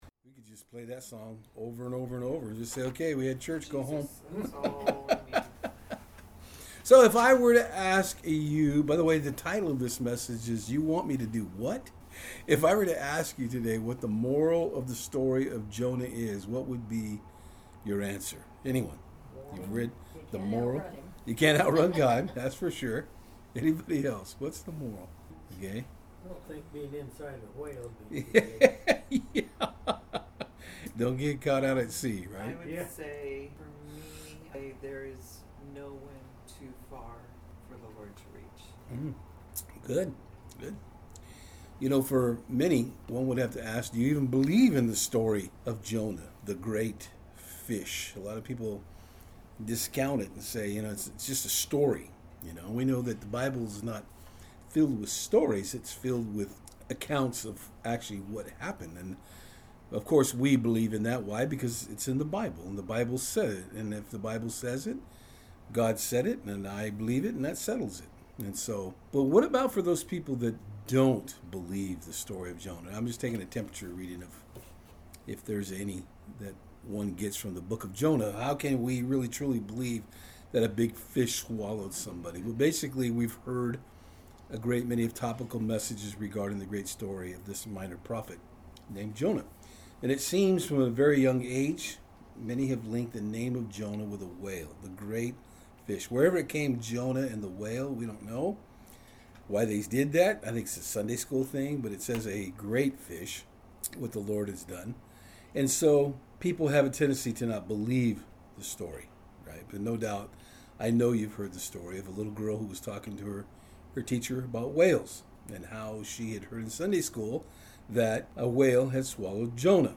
The Book of Jonah Service Type: Saturdays on Fort Hill We are looking at the Book of Jonah in our studies here on Sat Evenings.